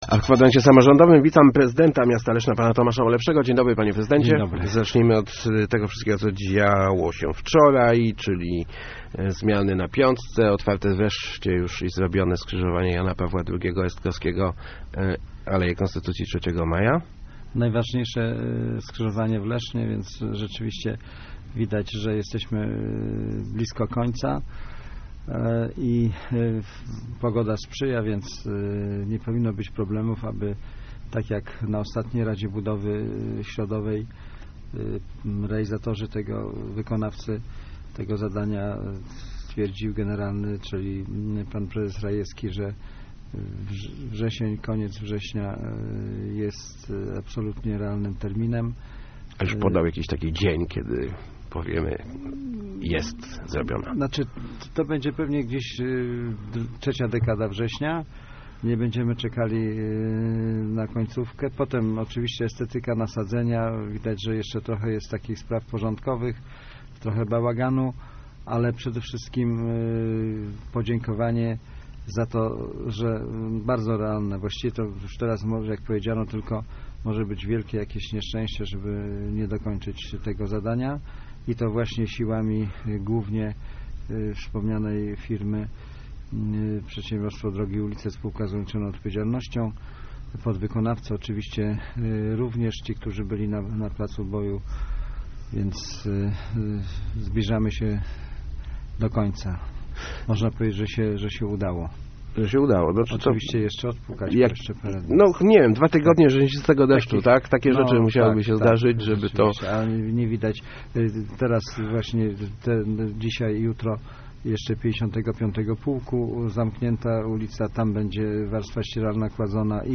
Gościem Kwadransa był prezydent Tomasz Malepszy.